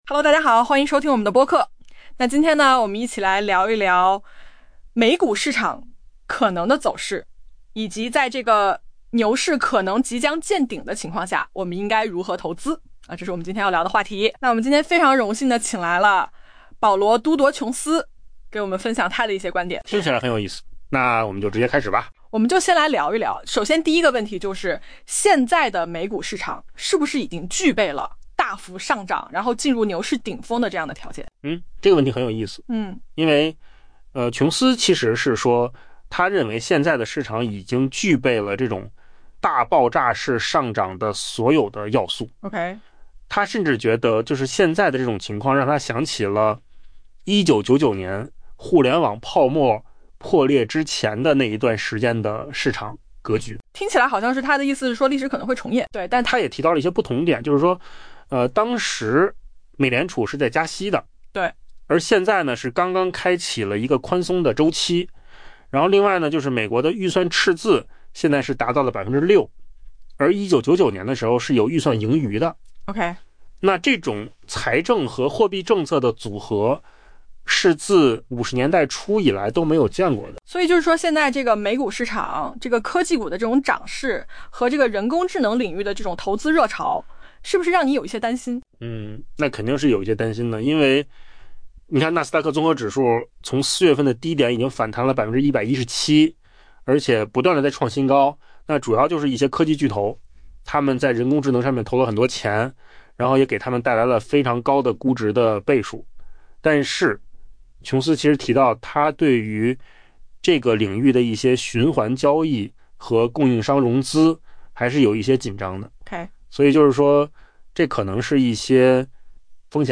【文章来源：金十数据】音频由扣子空间生